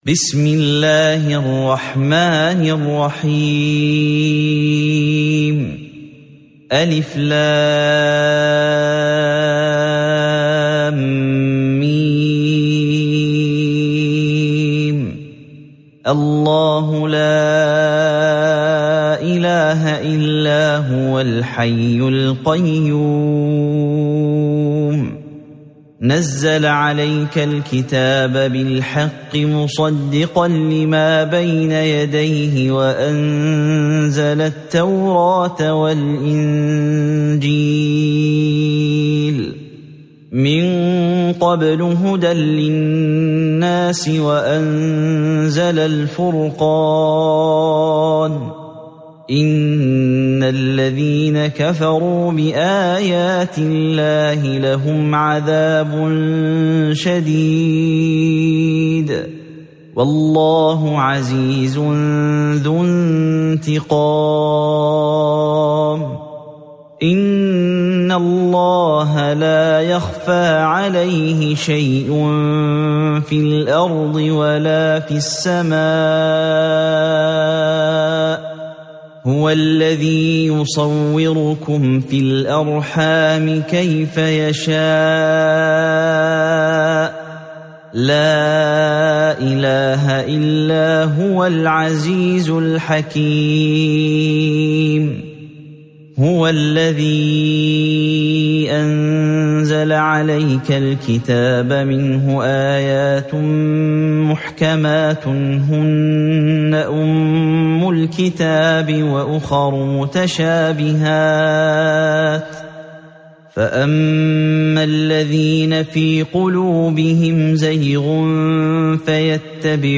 سورة آل عمران مدنية عدد الآيات:200 مكتوبة بخط عثماني كبير واضح من المصحف الشريف مع التفسير والتلاوة بصوت مشاهير القراء من موقع القرآن الكريم إسلام أون لاين